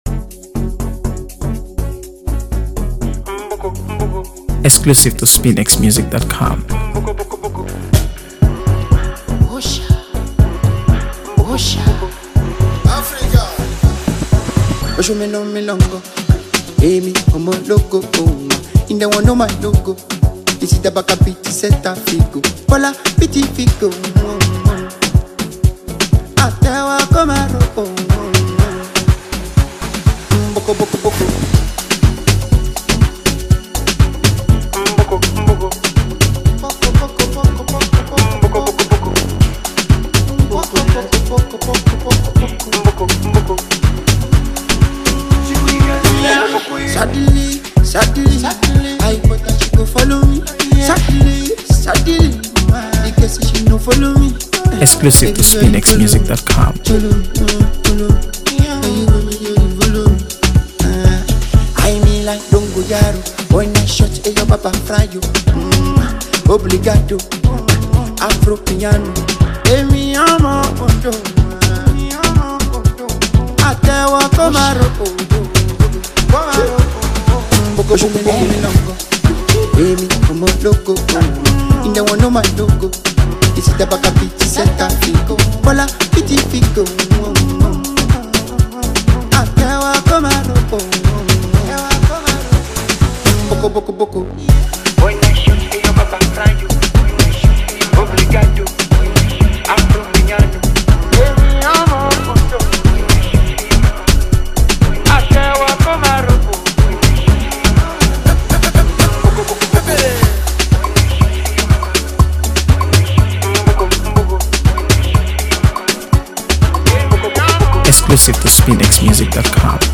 AfroBeats | AfroBeats songs
a captivating tune that blends infectious rhythms
signature smooth vocals